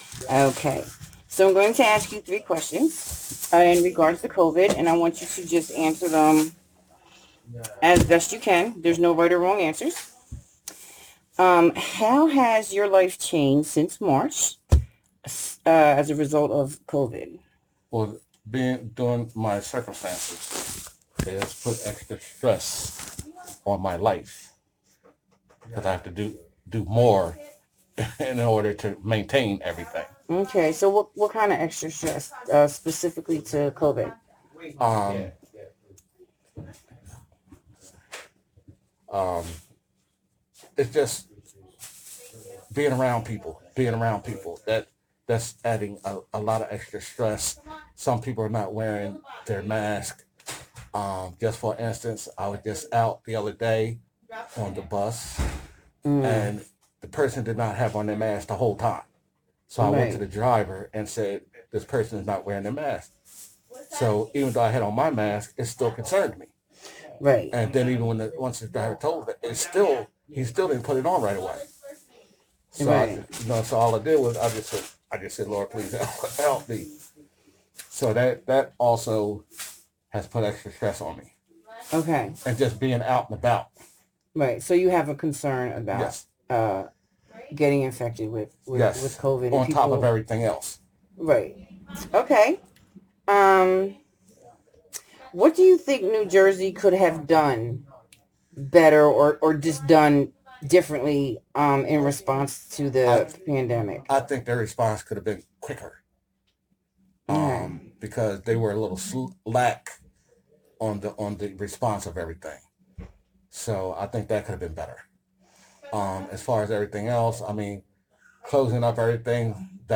Participant 130 Community Conversations Interview